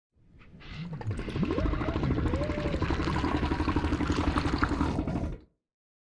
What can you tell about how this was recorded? * mono sounds